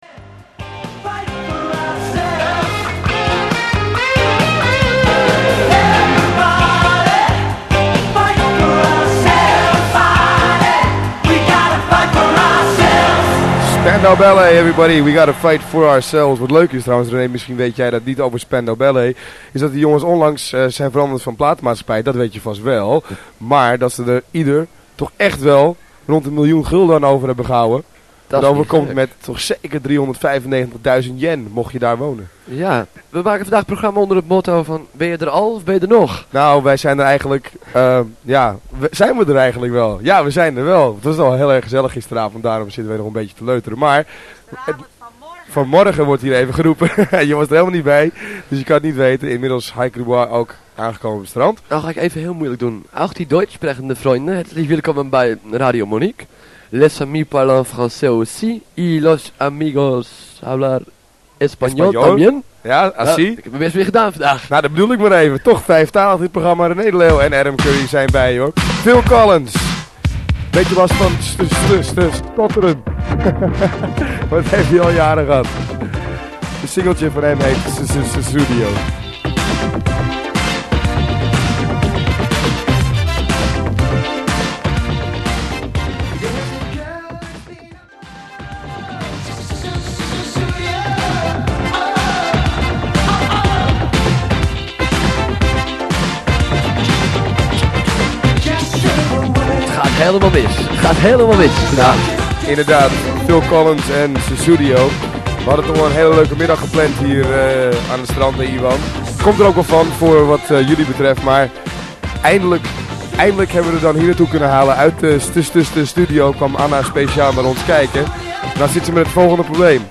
Radio Monique vanuit Playa D’Aro